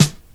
• Short Steel Snare Drum Sample D Key 12.wav
Royality free snare drum tuned to the D note. Loudest frequency: 1958Hz
short-steel-snare-drum-sample-d-key-12-83X.wav